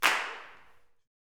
CLAPSUTC3.wav